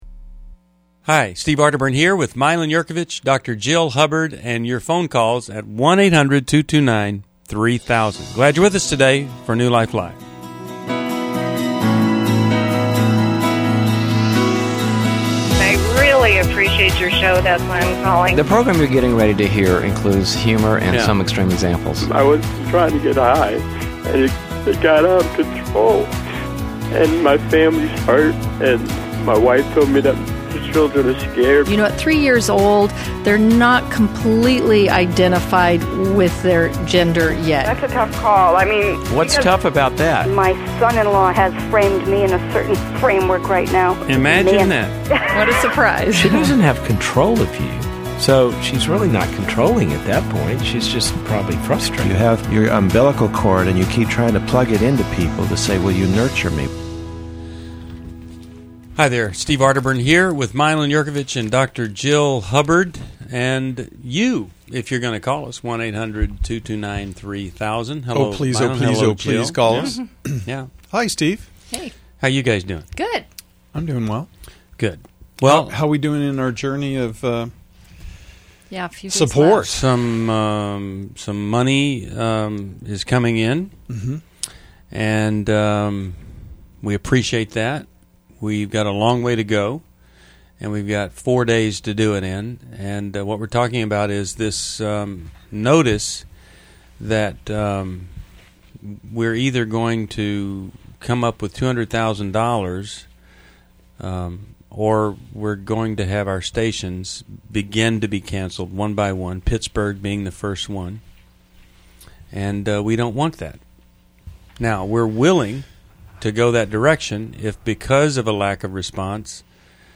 New Life Live: October 27, 2011 - Hosts tackle divorce, navigating a child's sexuality, and the concept of brokenness with insightful caller questions.